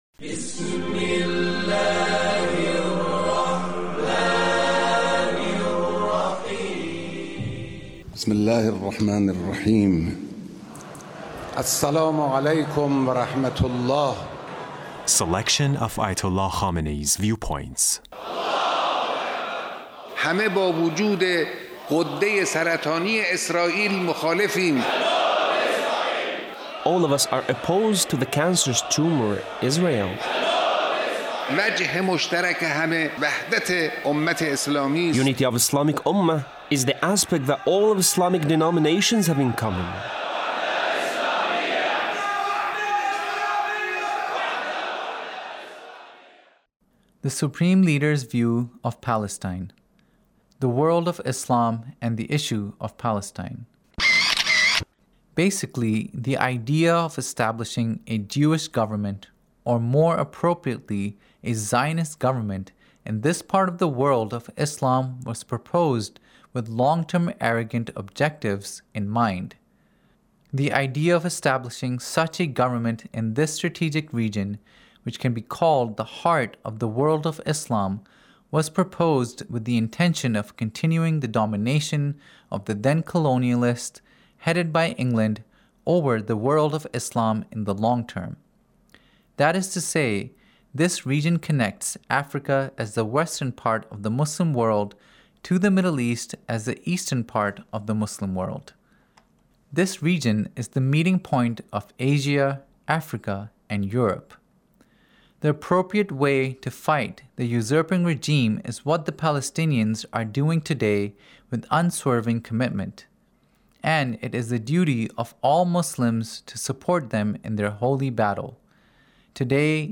Leader's Speech (1868)
Leader's Speech on Palestine